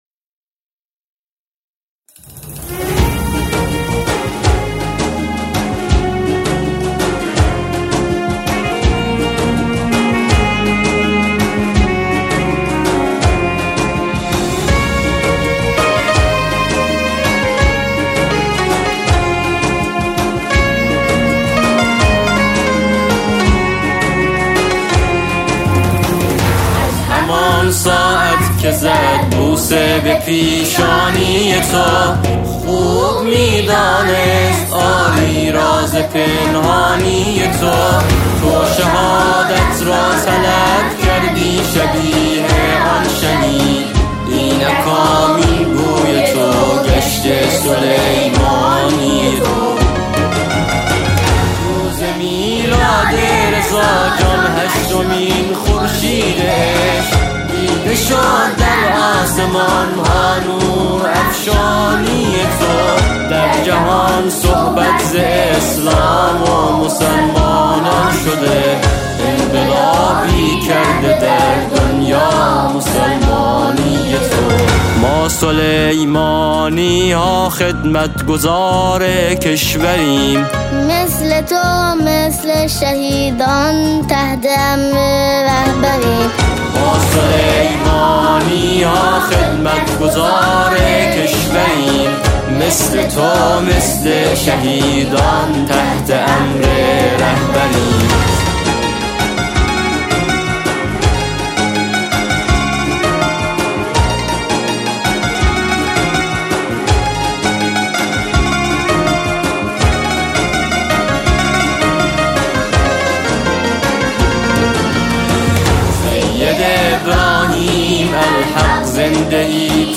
سرود شهید رئیسی